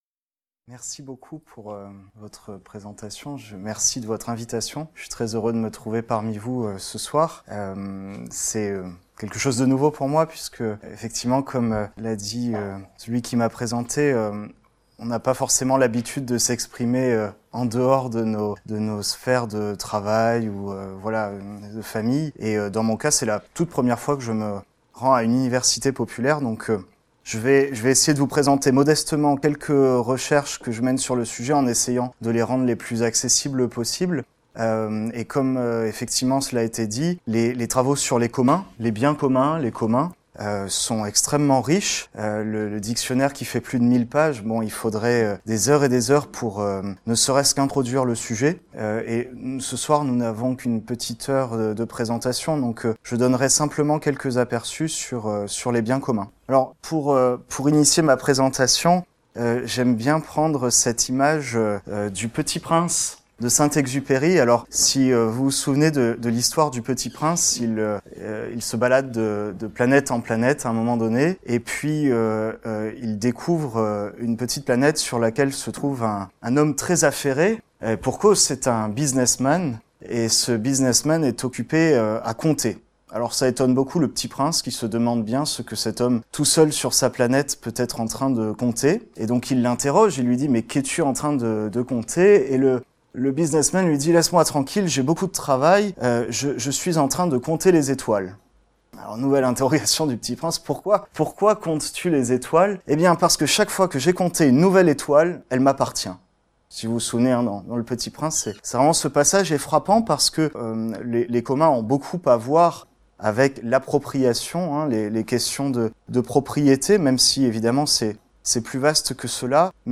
par GUILLAUME LECOINTRE, zoologiste, de l'Institut de Systématique, Evolution et Biodiversité, professeur au Muséum national d’Histoire naturelle | LA LAÏCITÉ DANS LES SCIENCES - conférence du 29/11/2017 à la Maison de la philosophie à Toulouse.